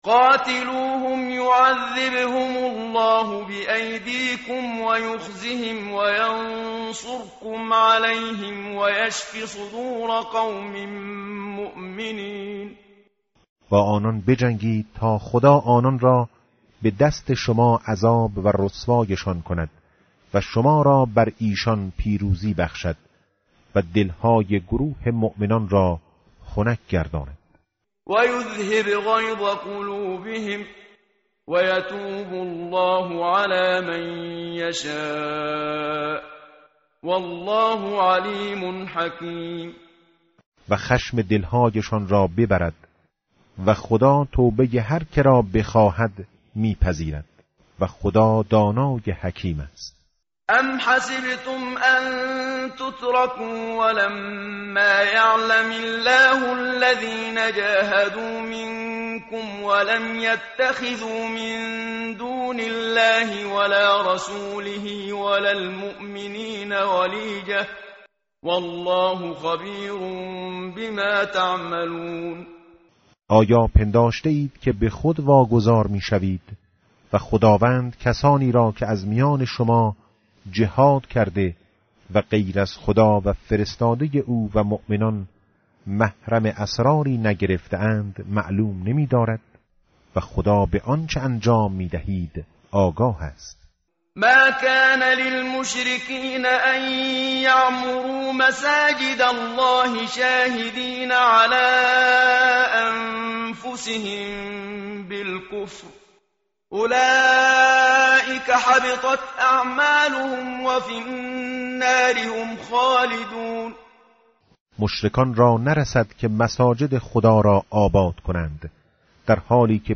متن قرآن همراه باتلاوت قرآن و ترجمه
tartil_menshavi va tarjome_Page_189.mp3